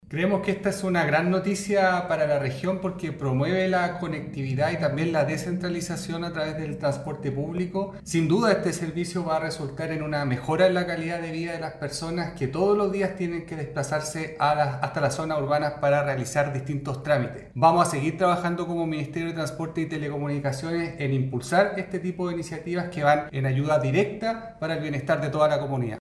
El director de la DTPR, Cristóbal Pineda, aseguró que este futuro servicio no sólo favorecerá a los habitantes de los lugares más remotas, sino que también representa un aporte en los procesos de descentralización.
cu-licitacion-transporte-interior-cristobal-pineda.mp3